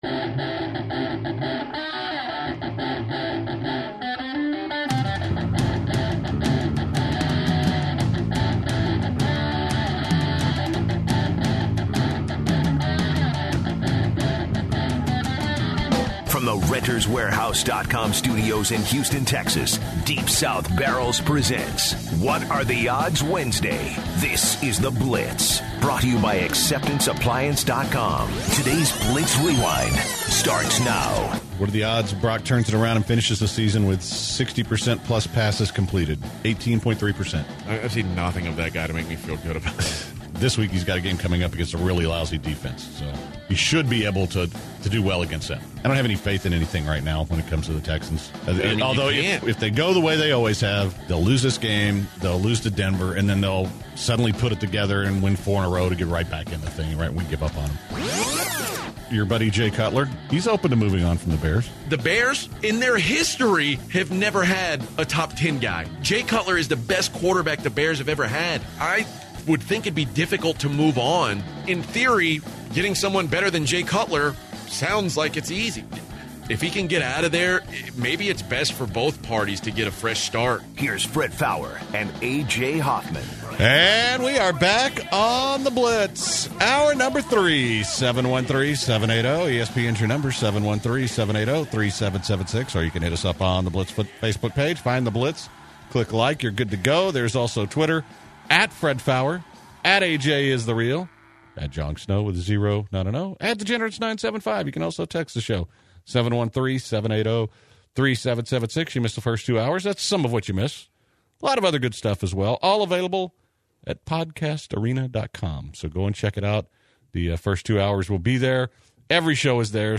The guys take more what are the odds questions from callers. The gem of the day is another clown story.